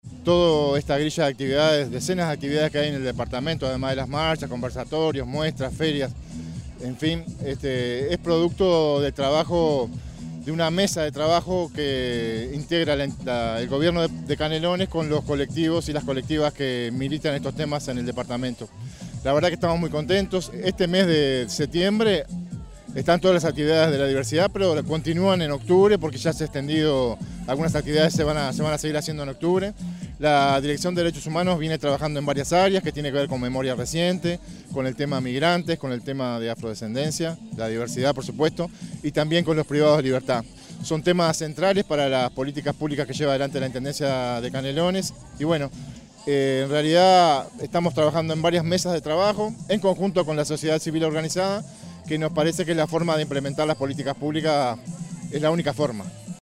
carlos_garolla_-_director_de_derechos_humanos.mp3